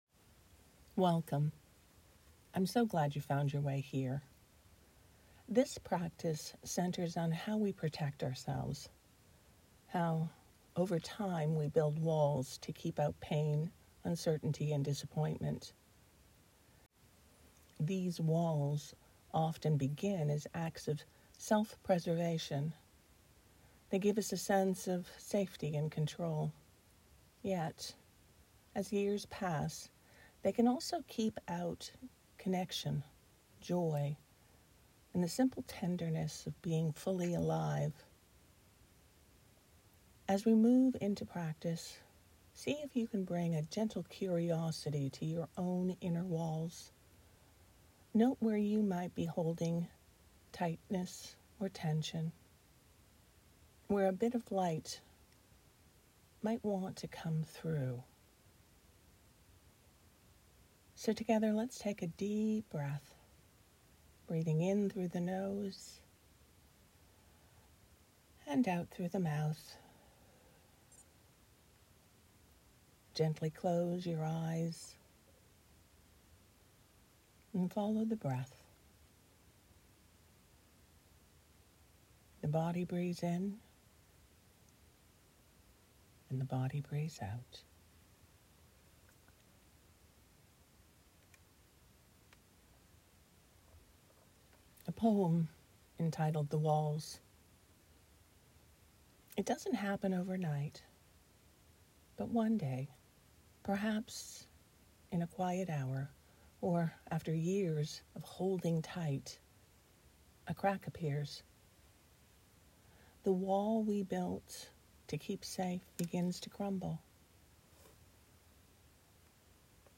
These meditations are offered freely.